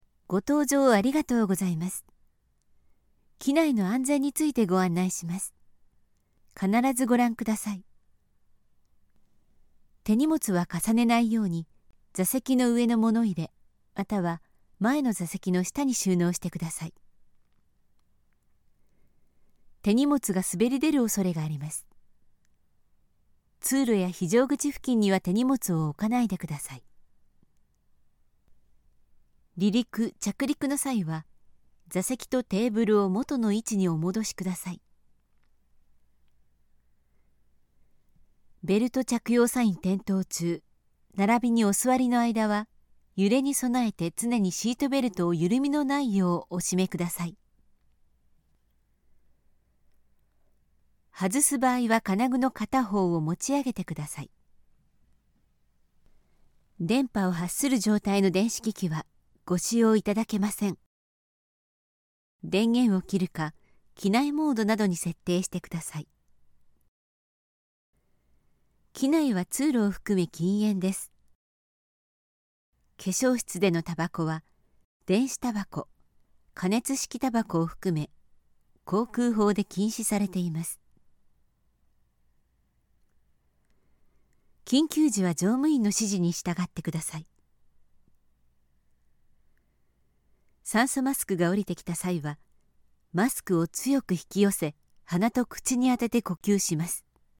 Commerciale, Polyvalente, Fiable, Corporative, Jeune
Corporate
Vidéo explicative